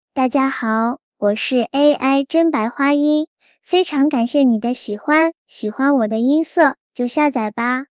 萝莉音